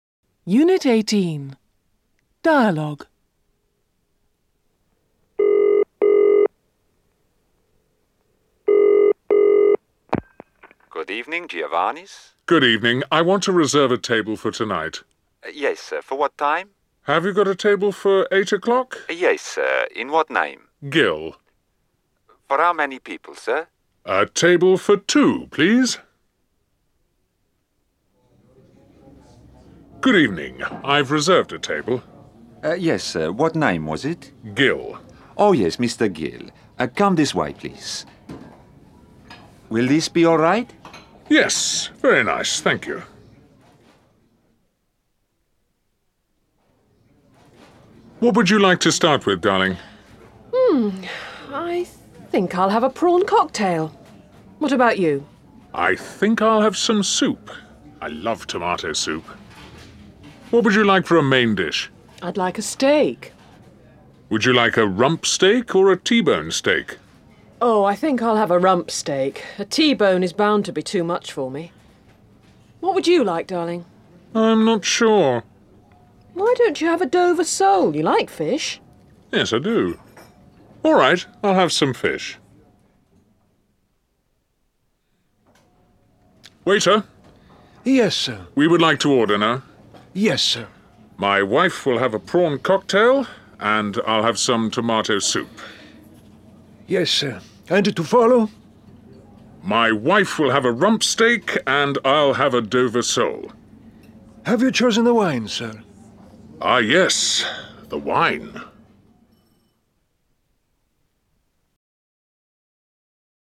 15 - Unit 18, Dialogues.mp3